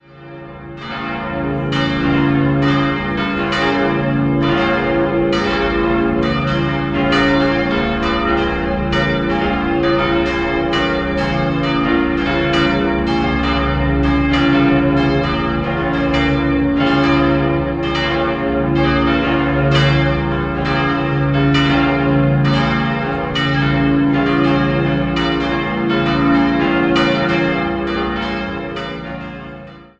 Nach einem verheerenden Ortsbrand entstand in den Jahren 1874 bis 1877 die heutige neugotische Pfarrkirche. Nach dem Zweiten Vatikanischen Konzil kam es zur Umgestaltung des Innenraums. 4-stimmiges Geläut: des'-f'-as'-b' (vertieft) Alle Glocken wurden im Jahr 1949 von Rudolf Perner in Passau gegossen.